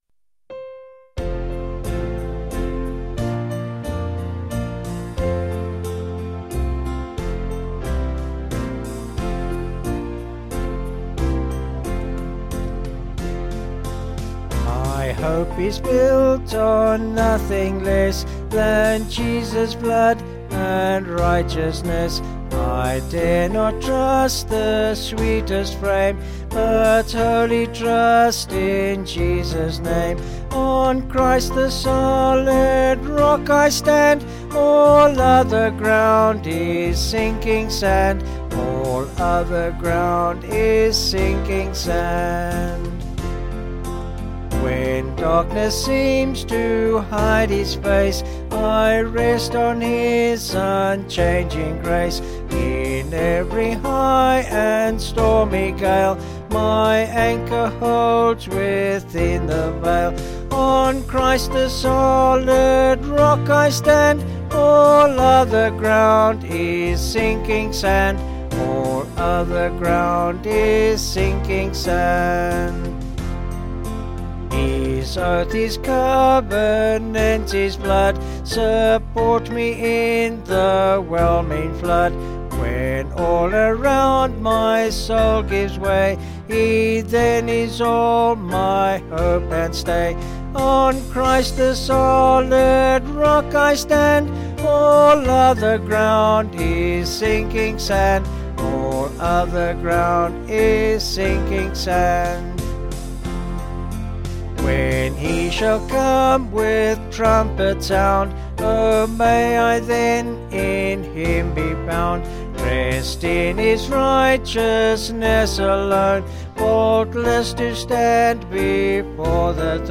Vocals and Band   262.9kb Sung Lyrics